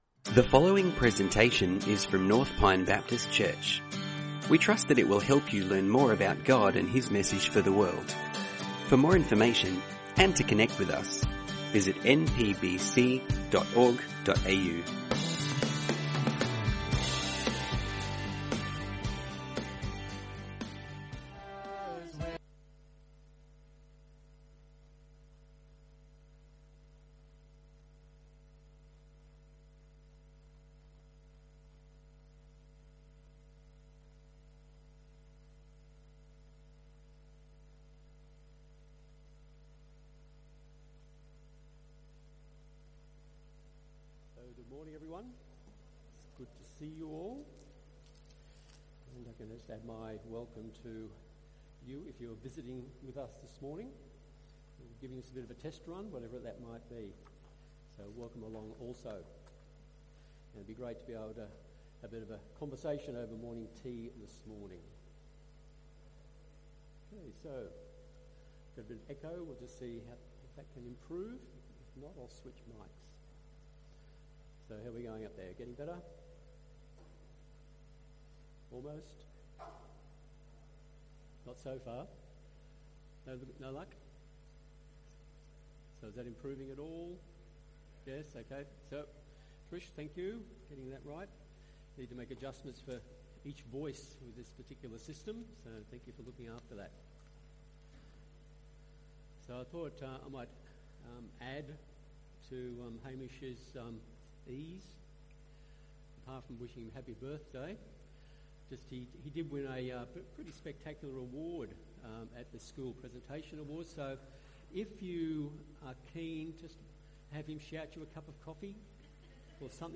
Sermons | North Pine Baptist Church